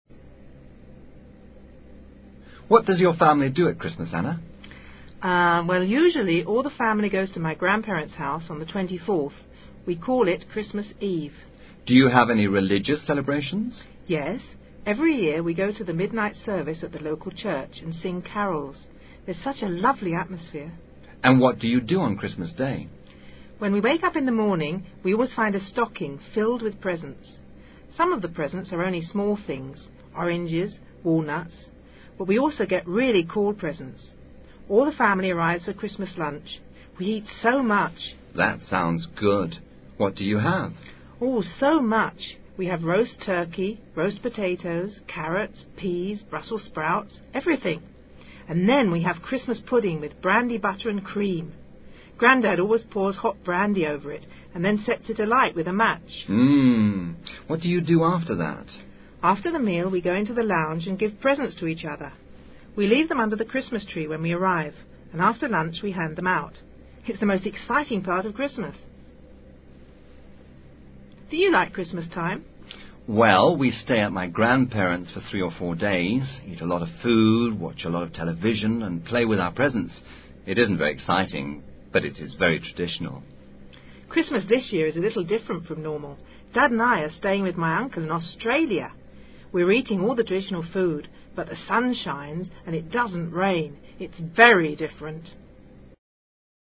Diálogo adecuado para trabajar las diferencias entre Present simple y Present continuous.